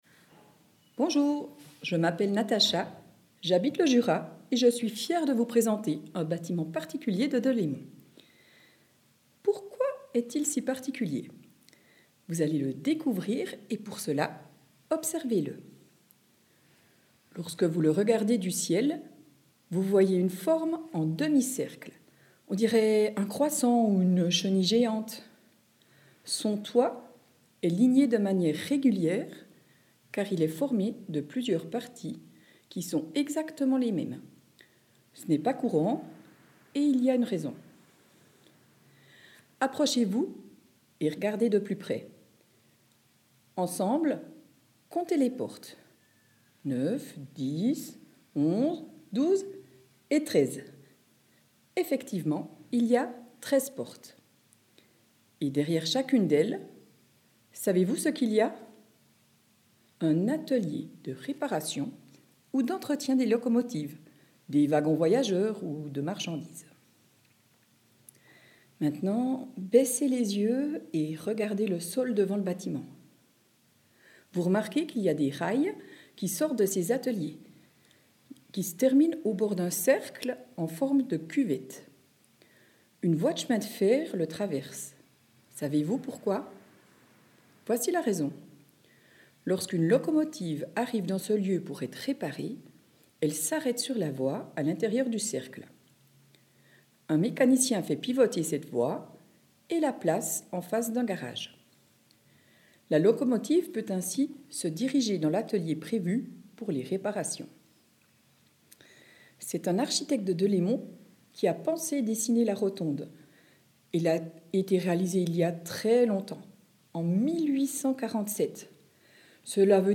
AUDIOGUIDE LA ROTONDE (RNS8)